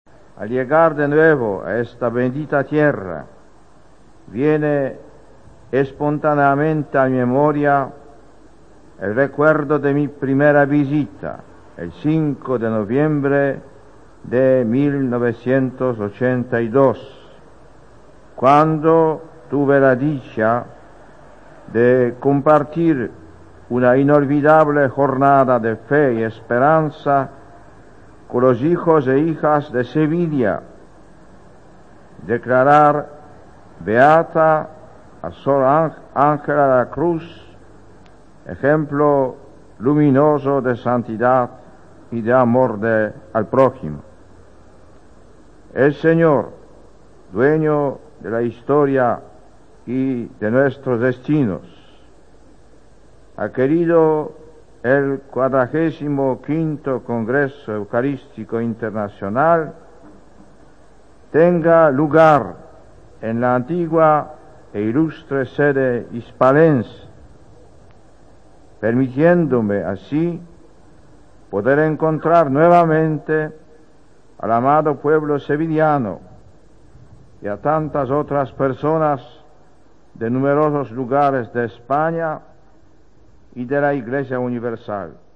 El Papa Juan Pablo II clausura en Sevilla el el XLV Congreso Eucarístico Internacional